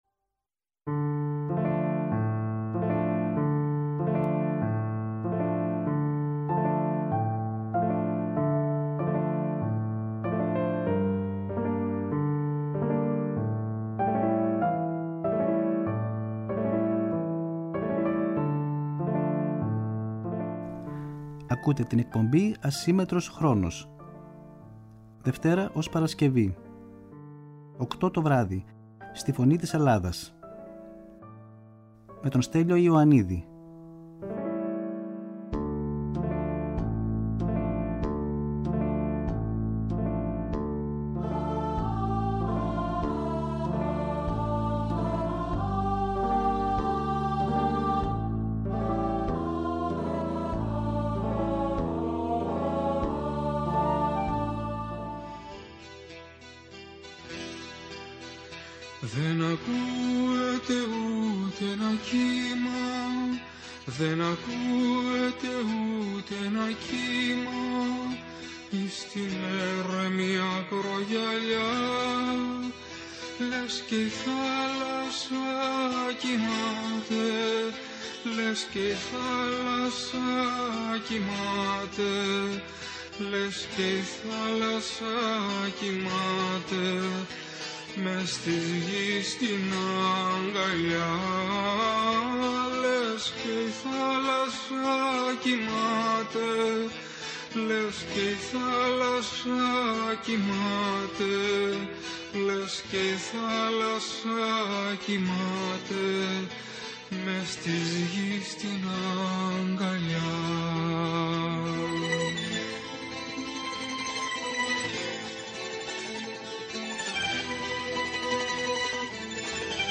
Μια εκπομπή με τραγούδια που αγαπήσαμε, μελωδίες που ξυπνούν μνήμες, αφιερώματα σε σημαντικούς δημιουργούς, κυρίως της ελληνικής μουσικής σκηνής, ενώ δεν απουσιάζουν οι εκφραστές της jazz και του παγκόσμιου μουσικού πολιτισμού.